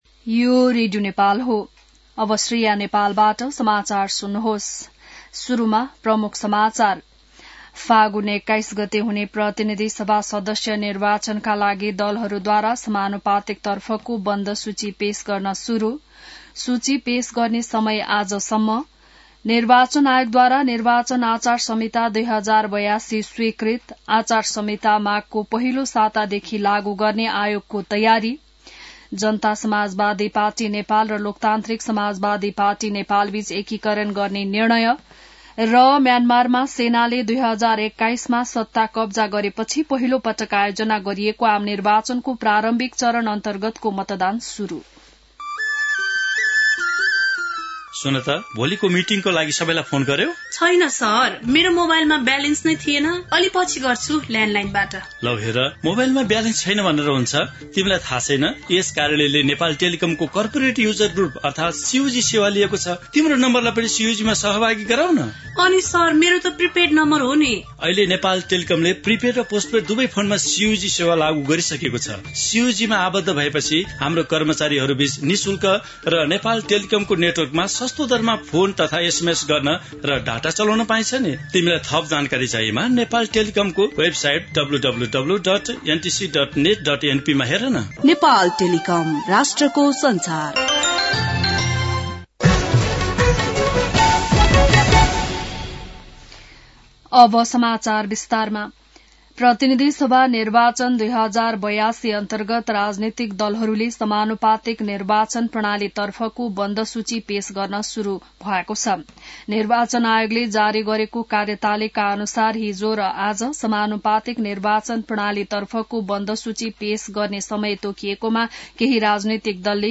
बिहान ७ बजेको नेपाली समाचार : १४ पुष , २०८२